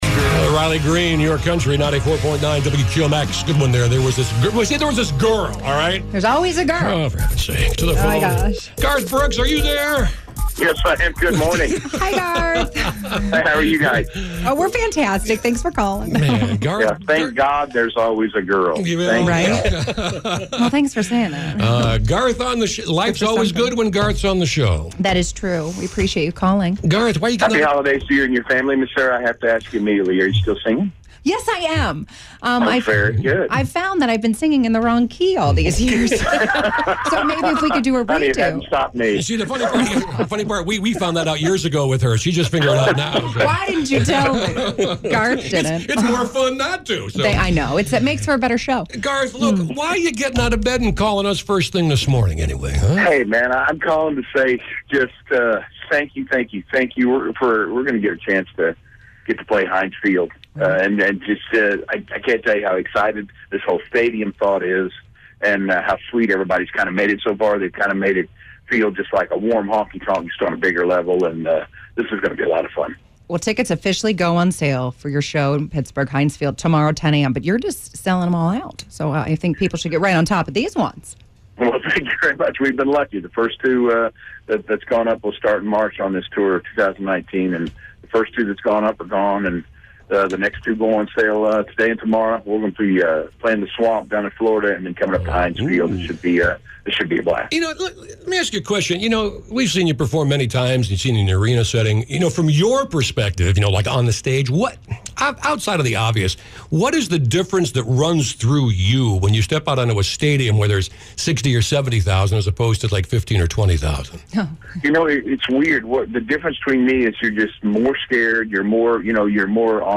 And, like I said- anytime Garth calls, we always answer! Here is talking about his new STADIUM tour: